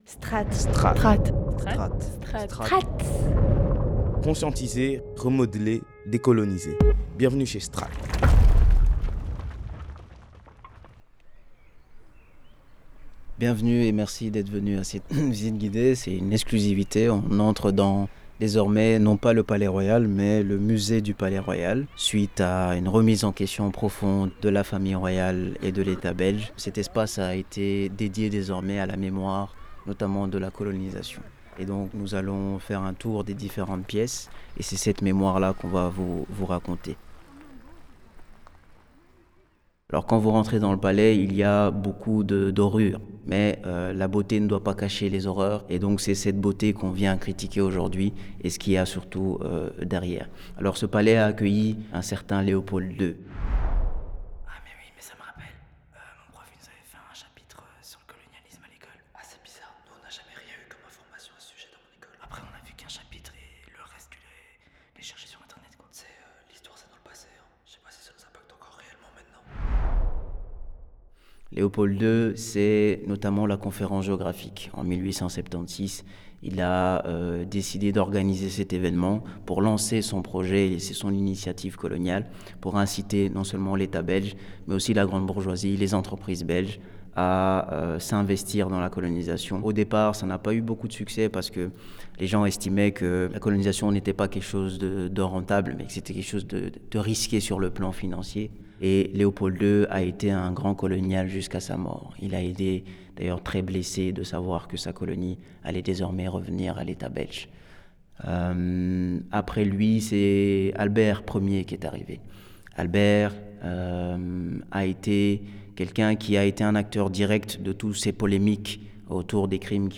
visite guidée